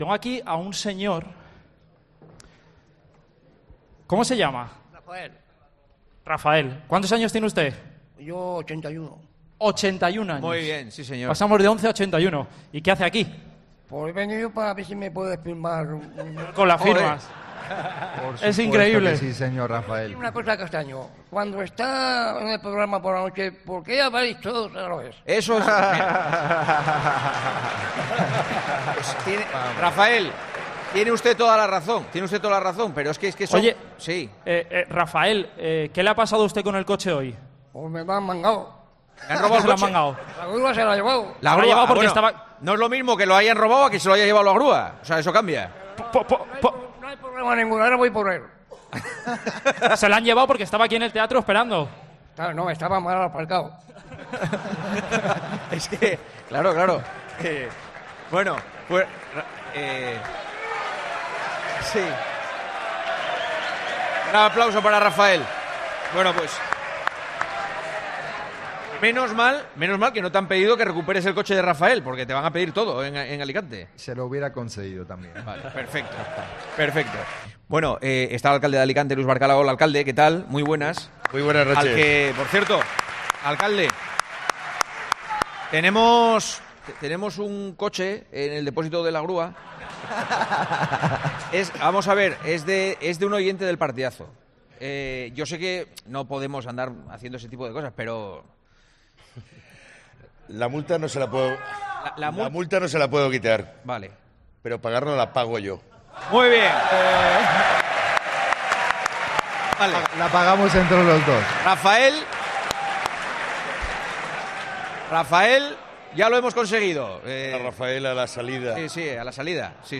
"Muy bien", señalaba entre aplausos Juanma Castaño.